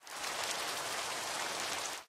rain3.ogg